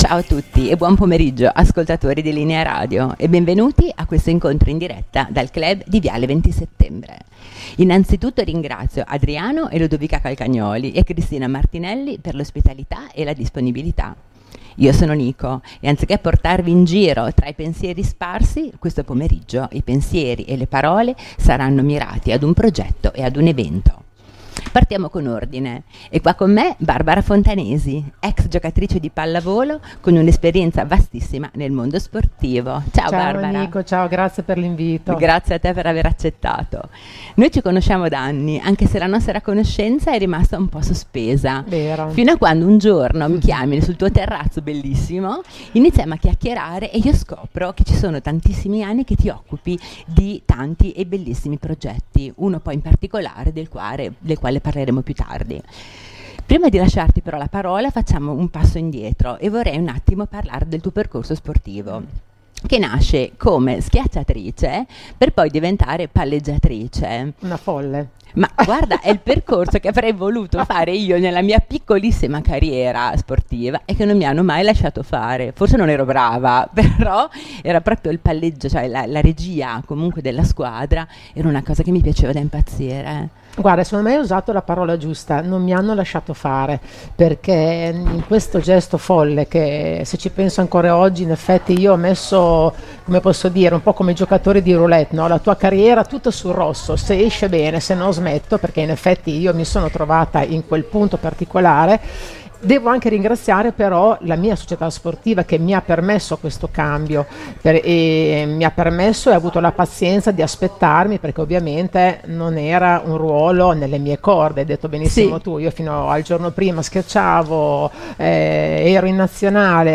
Le interviste di Linea Radio al Clhub di viale XX Settembre a Sassuolo